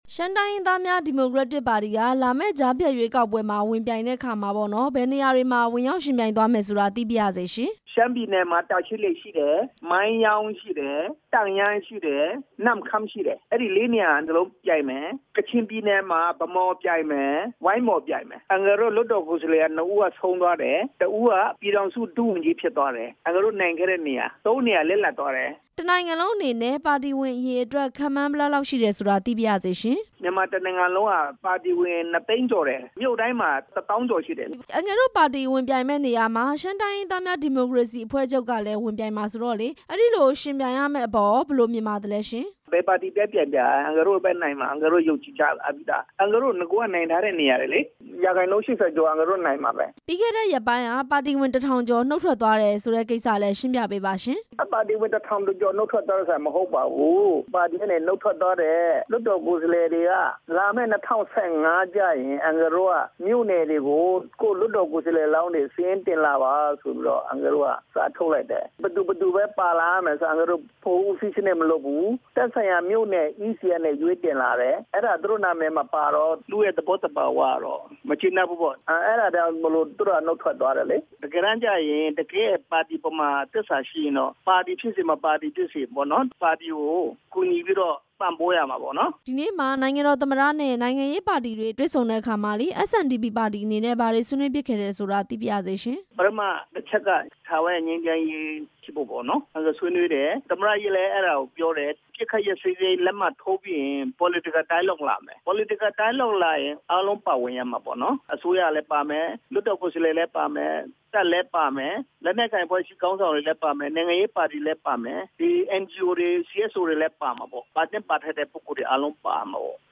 ရှမ်းတိုင်းရင်းသားများ ဒီမိုကရက်တစ်ပါတီအနေနဲ့ ဘယ်ပါတီနဲ့ပဲယှဉ်ယှဉ် အနိုင်ရဖို့ မျှော်မှန်းထားတယ်လို့ SNDP ပါတီ ဥက္ကဌ ဦးစိုင်းအိုက်ပေါင်းက RFA ကိုပြောပါတယ်။